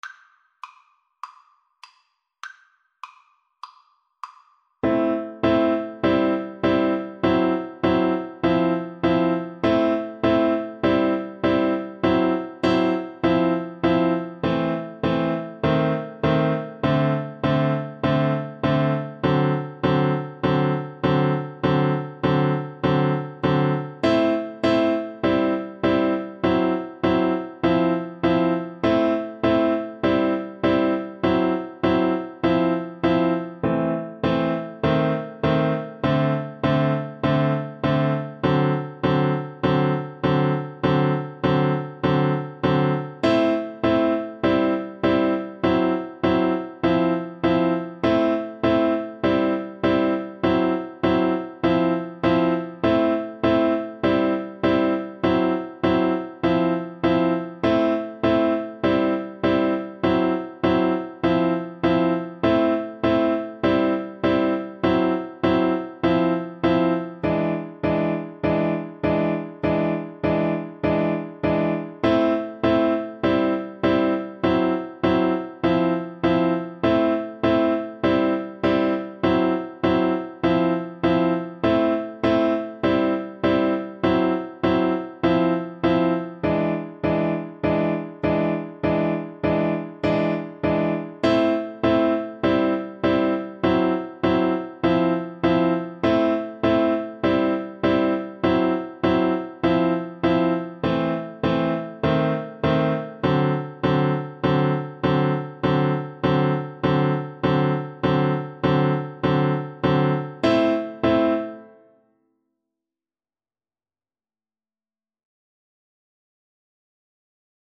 Swung
Piano Duet  (View more Easy Piano Duet Music)
Jazz (View more Jazz Piano Duet Music)